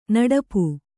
♪ naḍapu